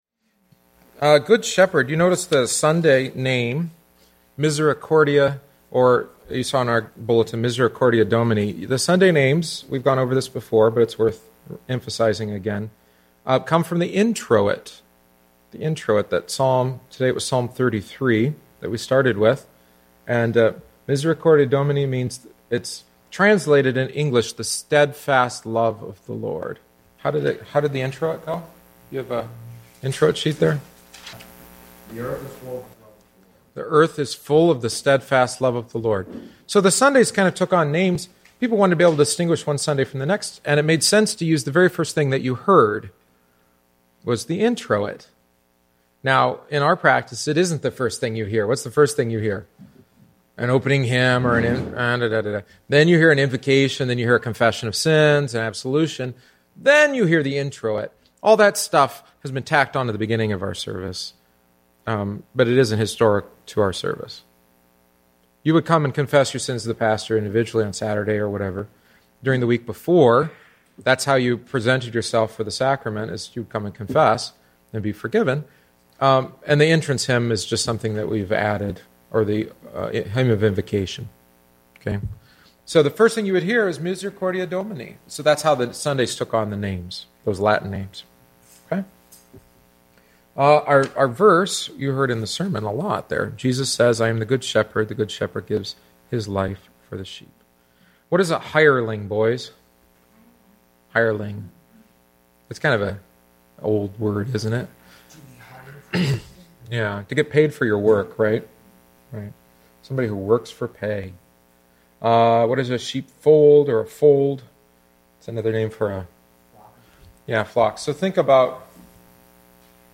Catechesis for Easter 2